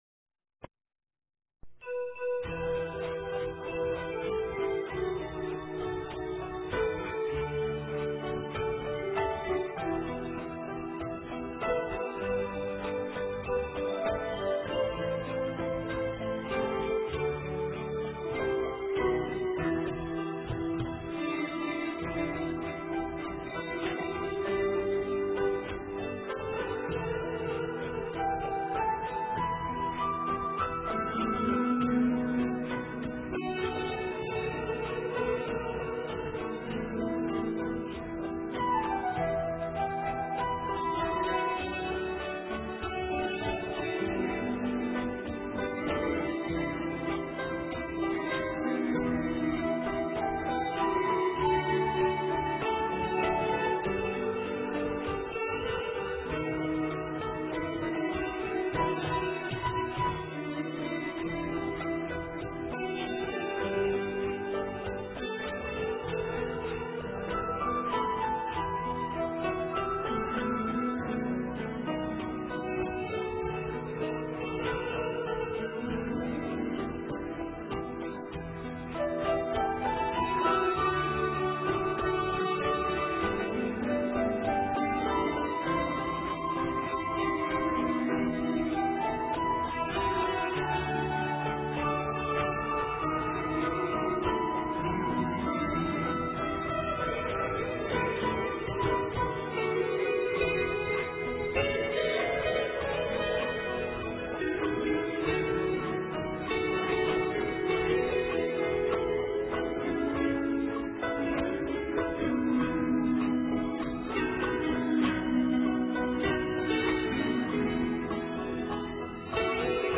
* Ca sĩ: Không lời
* Thể loại: Việt Nam